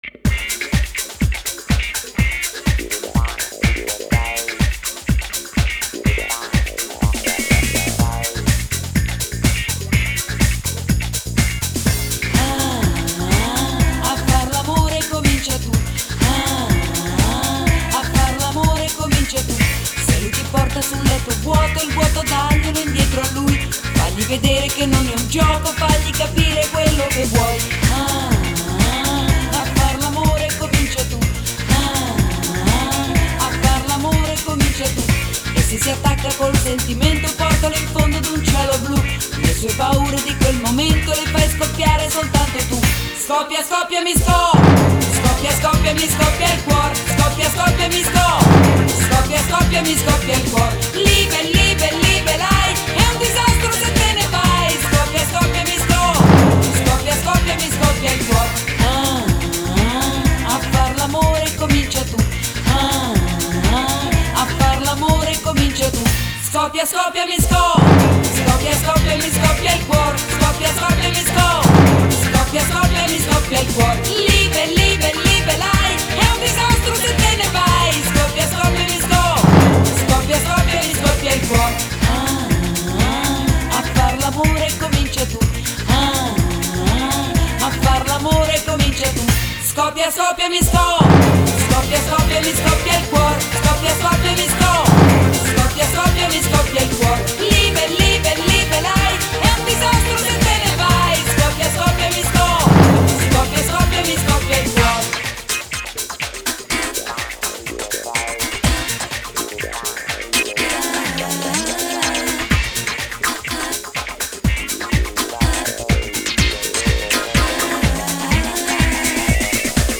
Только что нашёл у себя в коллекции на CD и оцифровал.